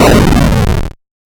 ChipTune Arcade FX 07.wav